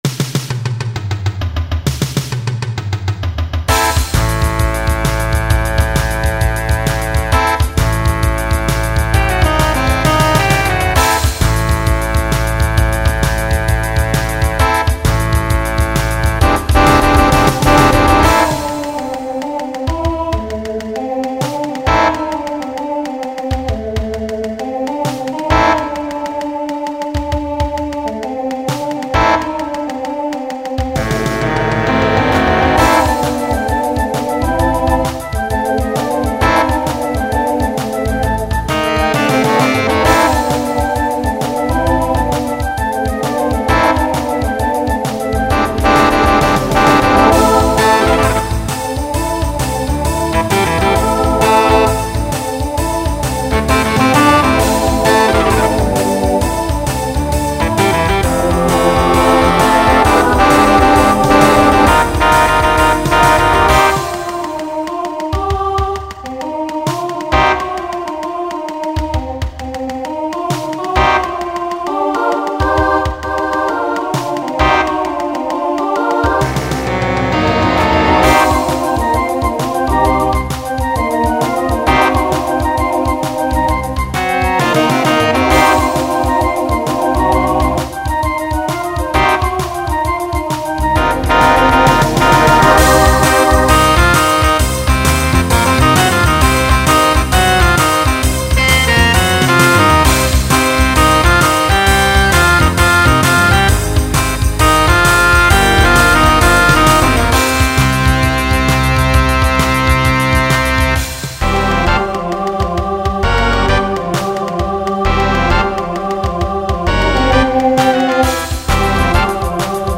Pop/Dance
Transition Voicing Mixed